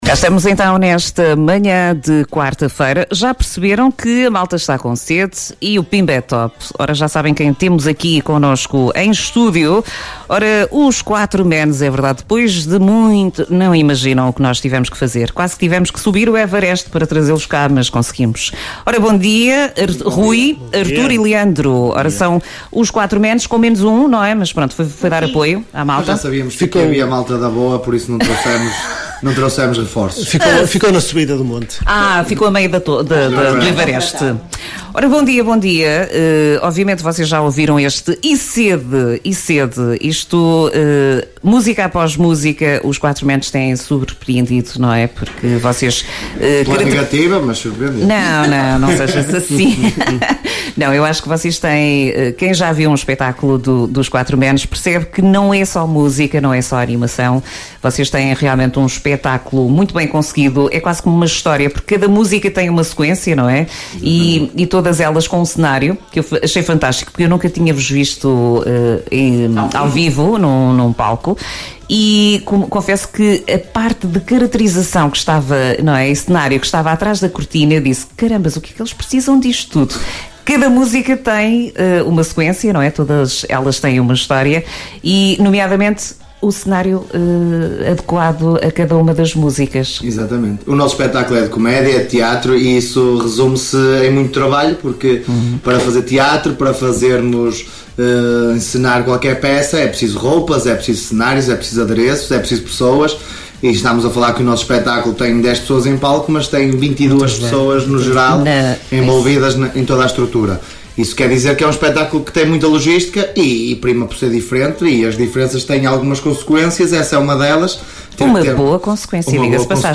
ENTREVISTA-4-MENS-ABRIL-2026.mp3